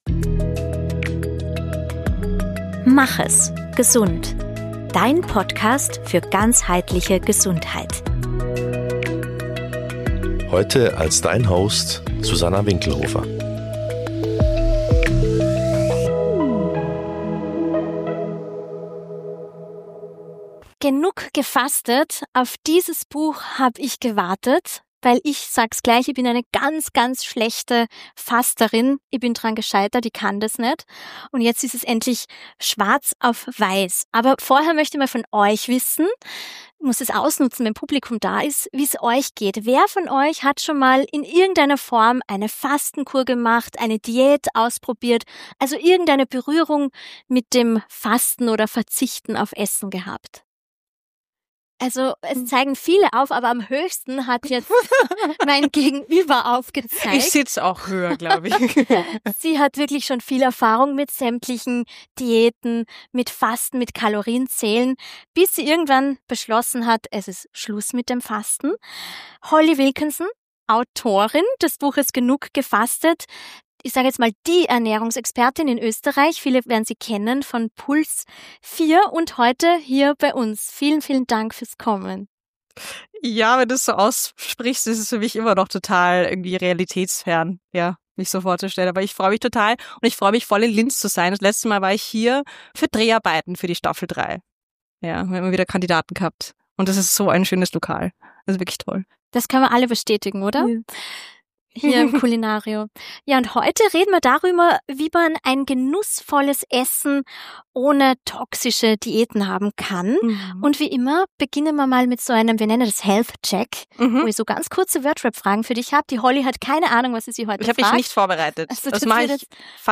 Im Kulinario Linz sitzt sie bei unserem Live-Podcast vor Publikum.
Ein Gespräch wie ein Befreiungsschlag.